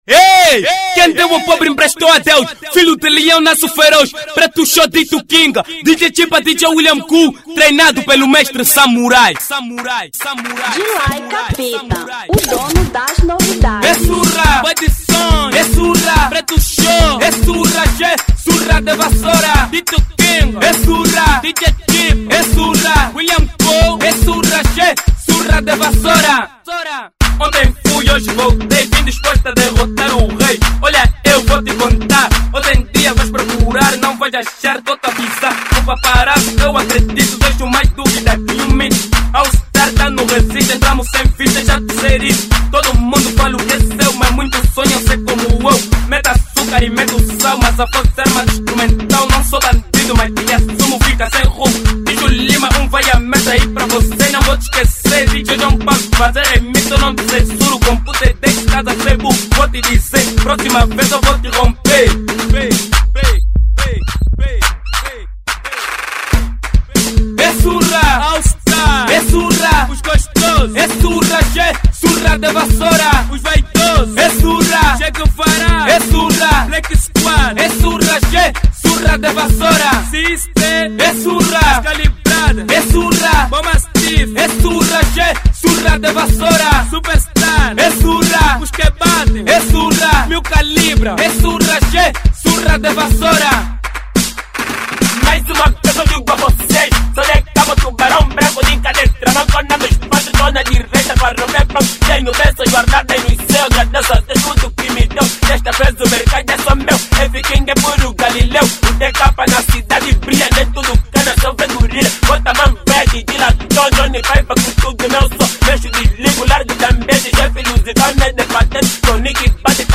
Kuduro 2007